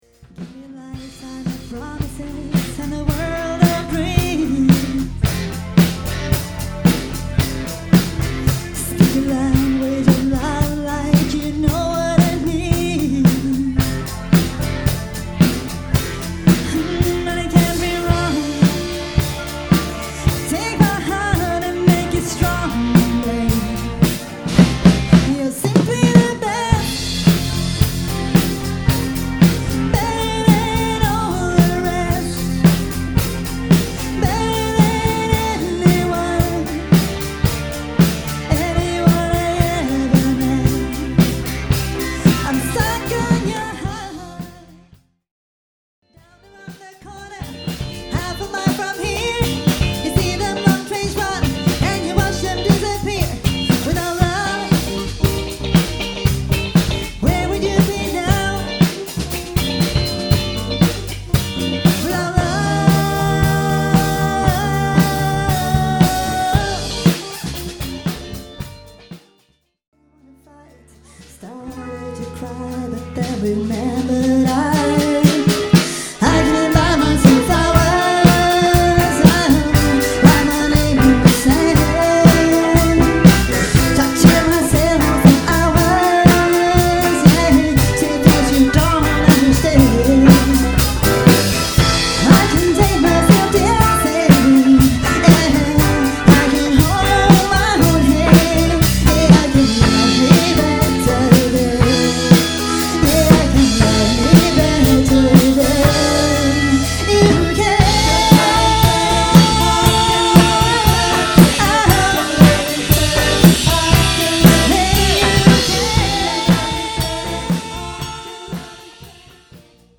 Partybandet för oförglömliga fester!
• Coverband
• Popband